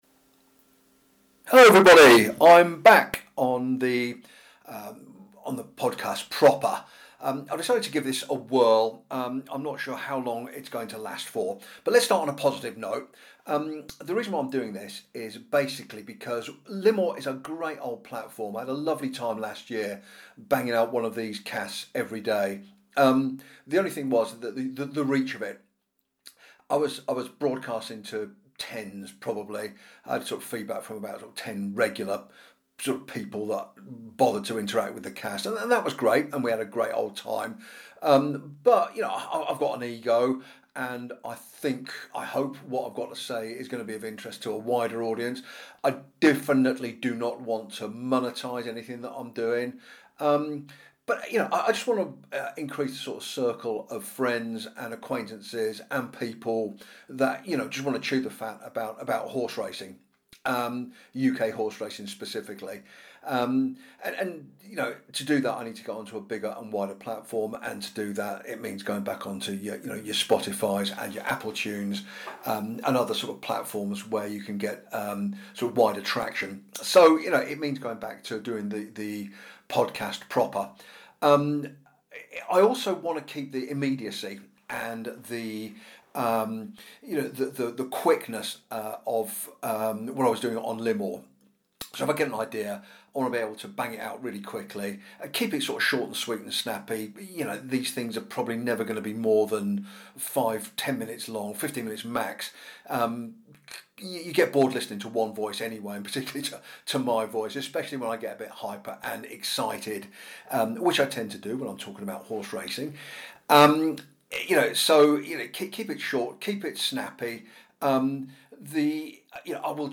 A return of the podcast (proper), attempting to retain the 'immediacy' and intimacy of the Limor casts (meaning limited post-recording sound engineering and plenty of 'umms' and 'ahhs') but on wider-reach platforms.